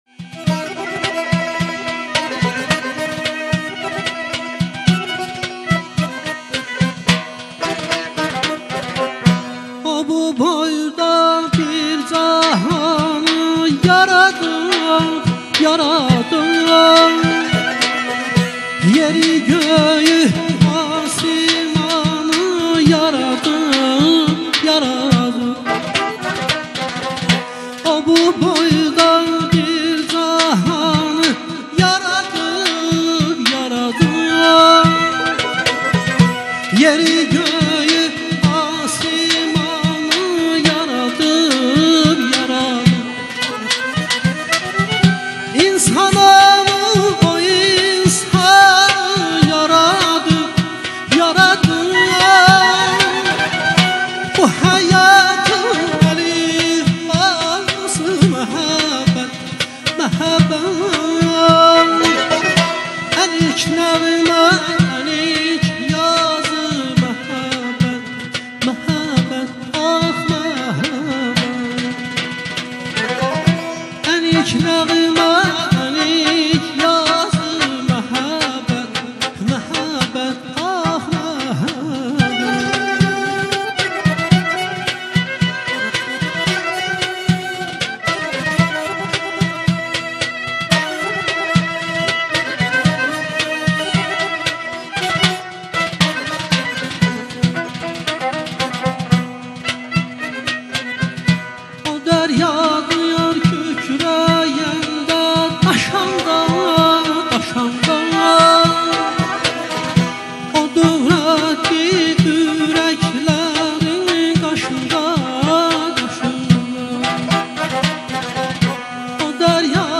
Canlı ifa